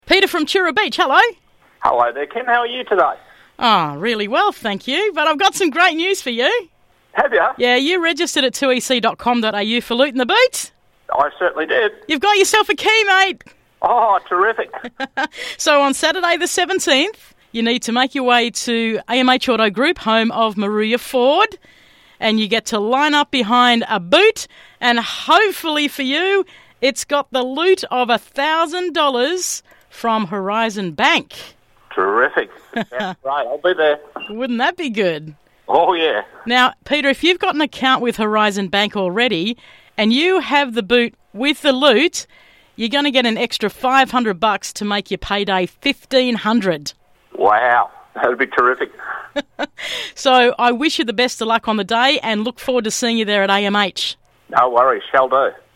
I'm having so much fun calling registered listeners for this great competition. Everyone I call is ecstatic and ready to be at Moruya Ford for the big finale on October 17th.